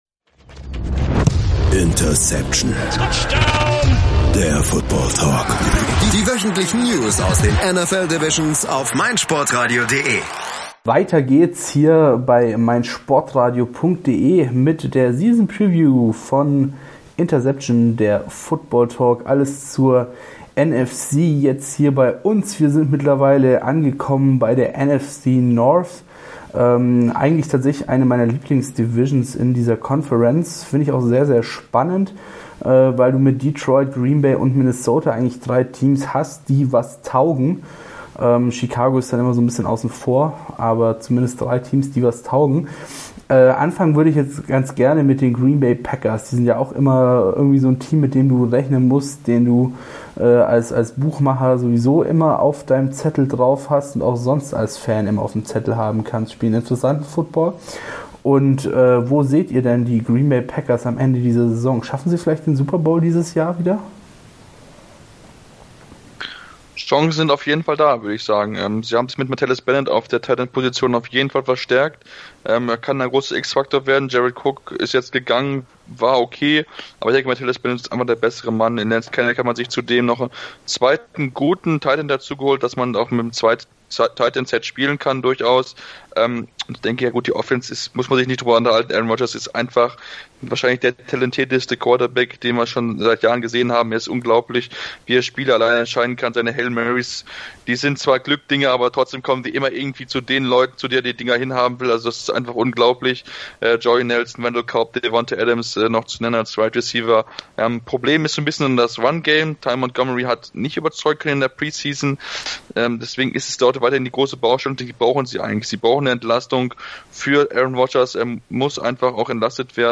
NFL Season Preview - NFC North ~ Interception - Der Football-Talk Podcast